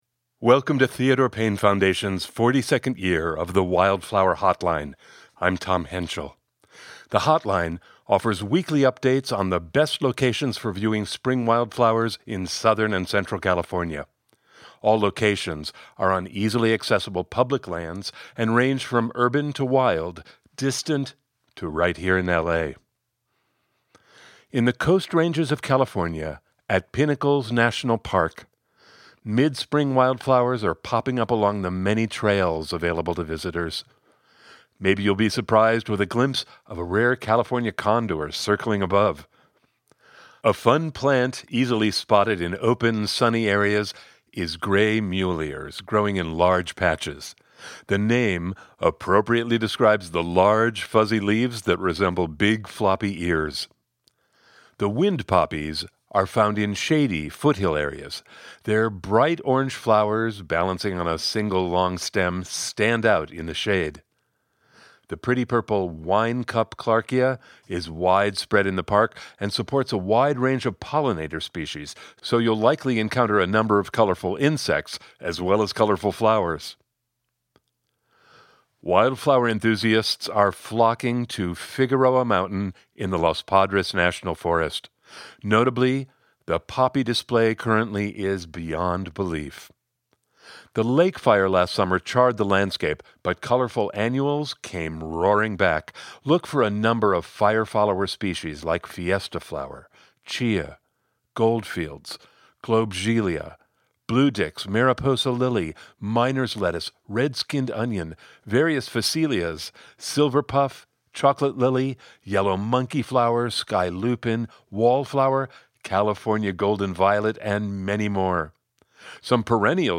Hear weekly recorded wildflower reports, narrated by Emmy Award-winning actor Joe Spano, the Voice of the Wild Flower Hotline. New reports are released every Friday, March through May!The acclaimed Theodore Payne Wild Flower Hotline, founded in 1983, offers free weekly online and recorded updates – posted each Friday from March through May – on the best locations for viewing spring wildflowers in Southern and Central California.